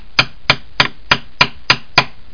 HAMMER.mp3